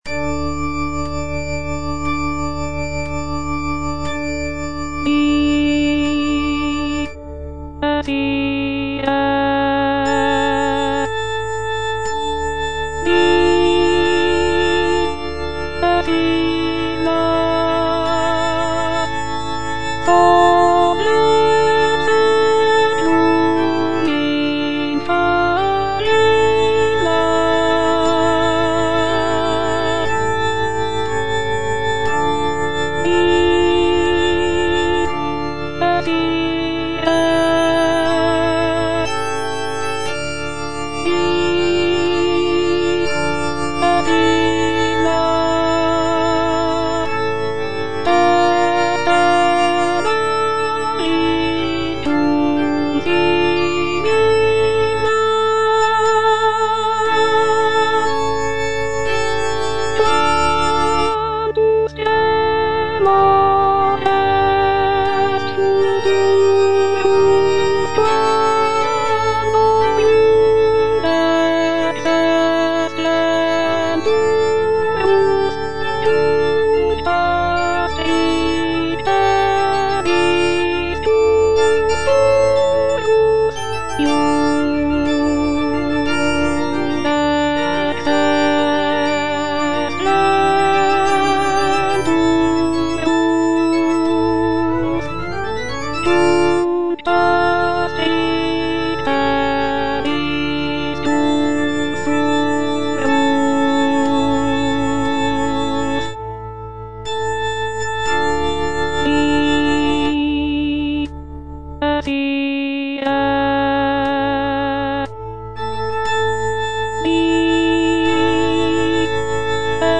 Alto (Voice with metronome) Ads stop
is a sacred choral work rooted in his Christian faith.